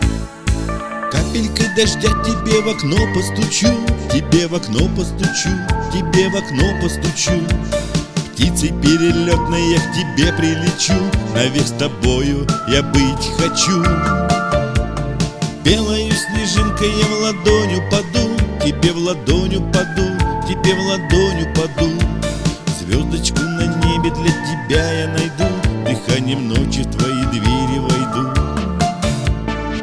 • Качество: 192, Stereo
русский шансон